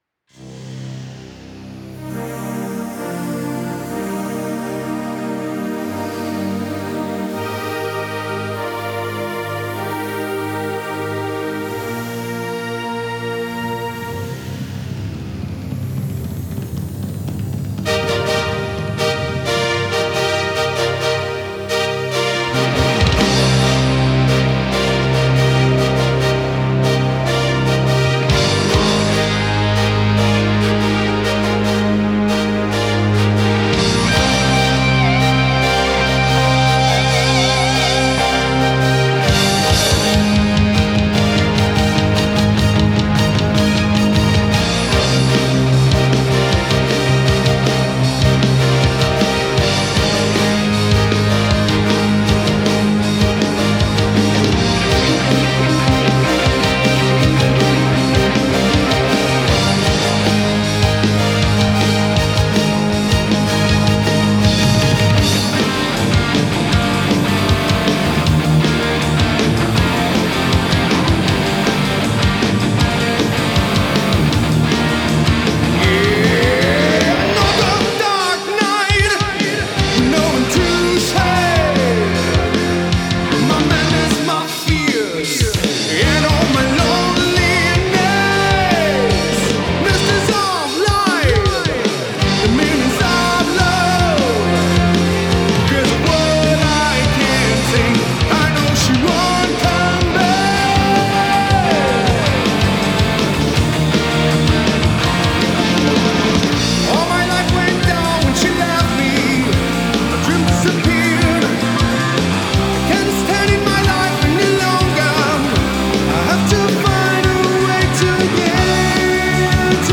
Guitars
It was recorded with my Suhr Classic with the DiMarzio Virtual Vintage pickups.
The only effects used was a bit of EQ, u-he's Colour Copy, and Dragonfly's Plate Reveb.